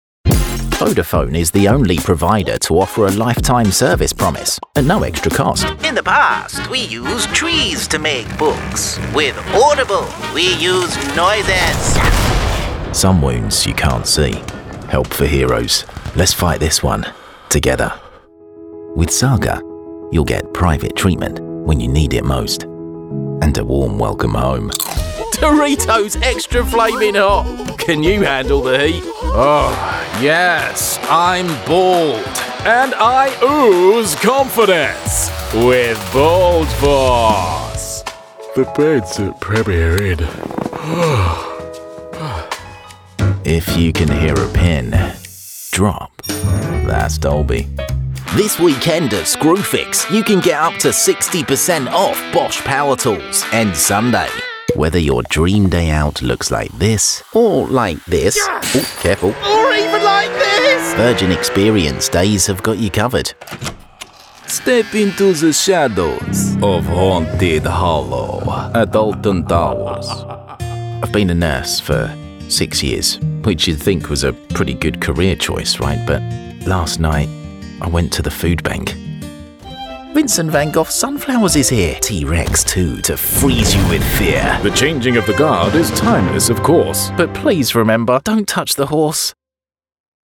Male & female announcers bring a professional sound to your next voice project.